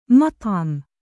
音标：maṭʿam